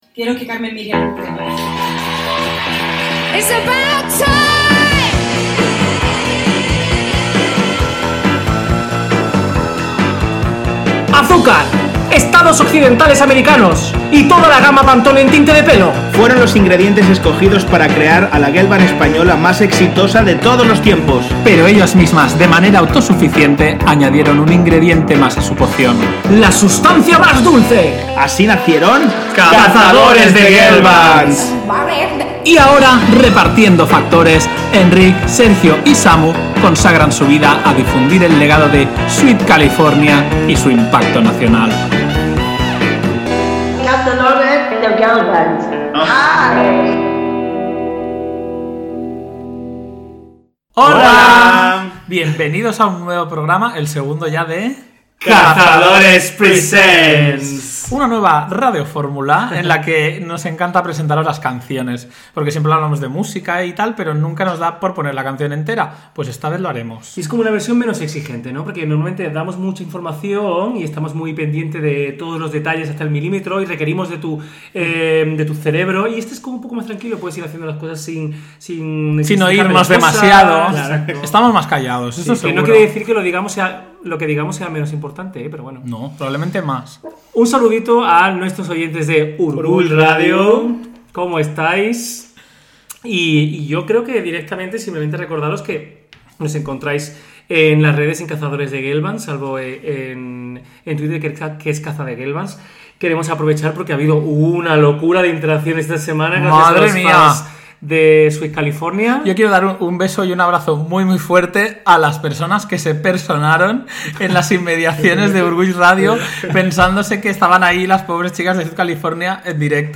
17 temes musicals que serveixen per recordar el contingut del programa anterior.